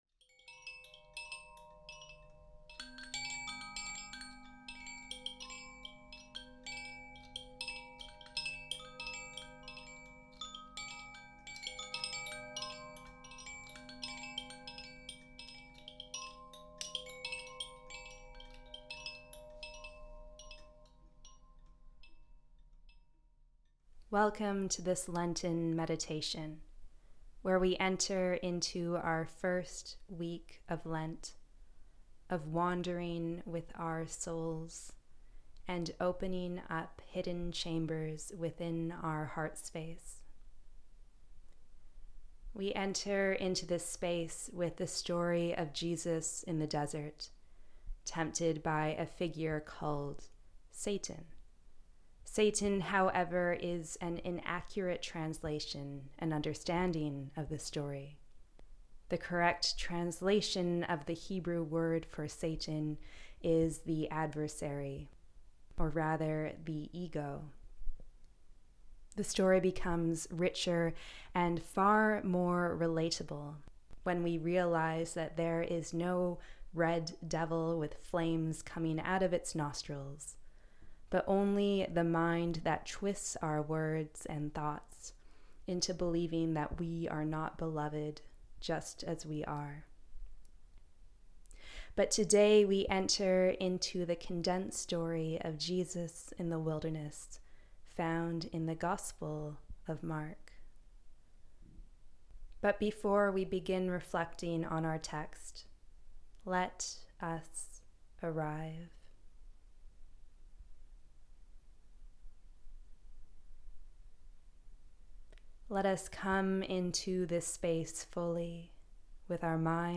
Lent Meditation